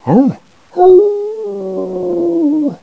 howl1.wav